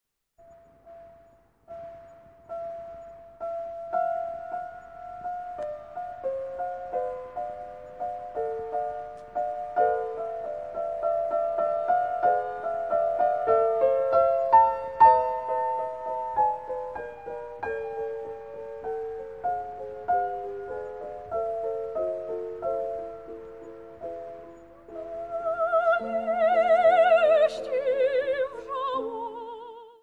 Fortepian